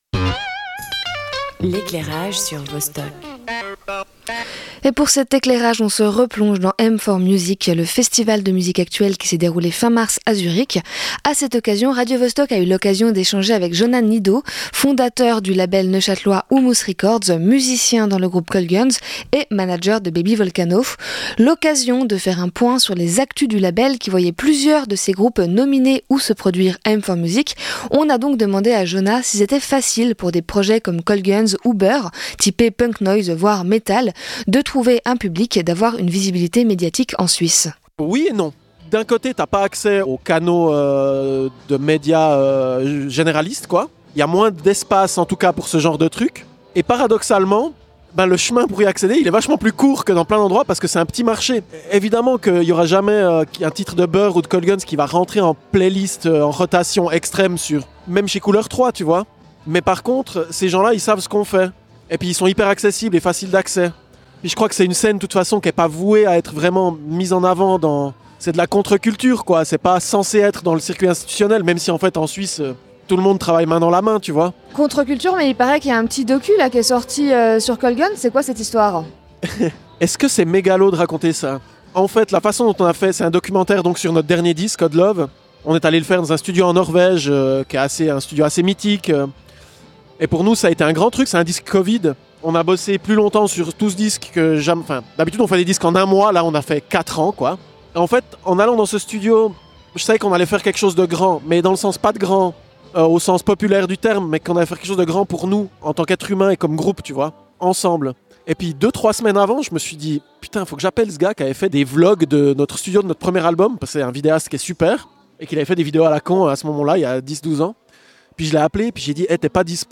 Radio Vostok s’est rendue au festival M4MUSIC à Zürich en mars.